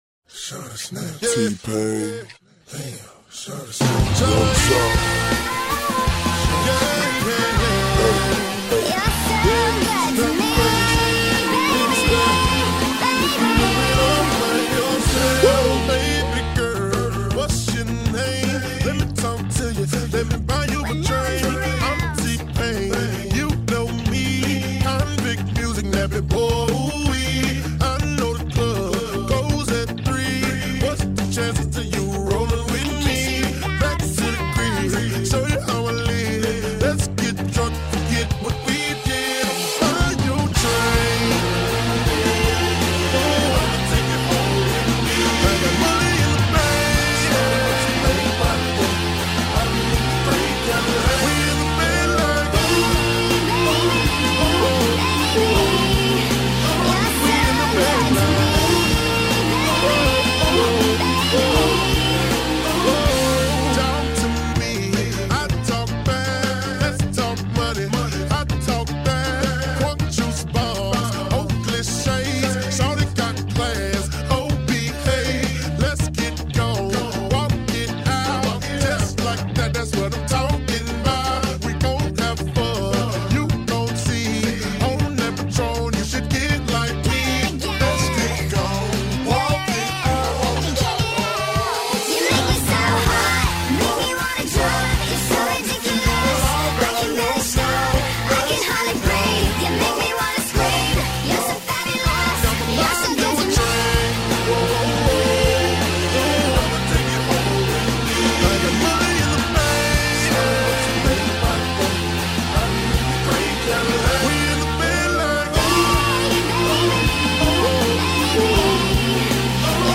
it's sort of got a chopped and screwed thing happening.
Filed under: Mashup | Comments (6)